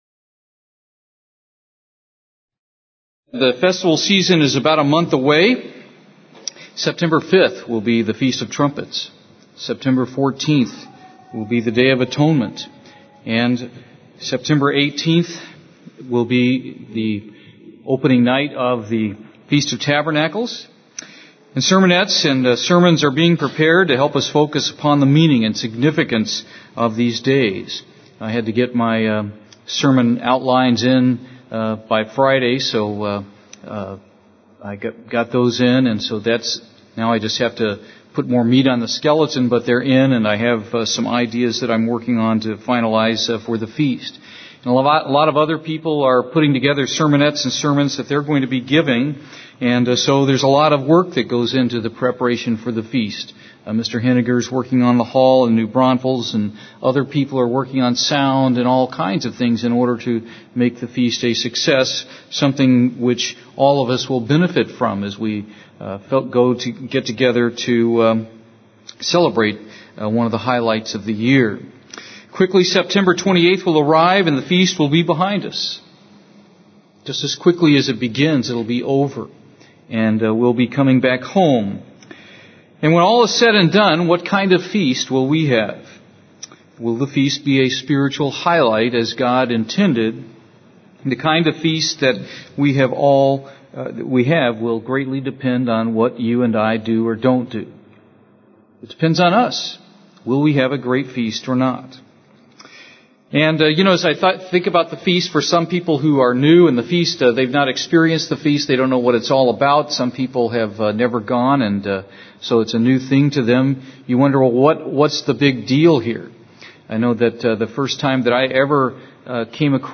He presents them using the acronym F-E-A-S-T. UCG Sermon Studying the bible?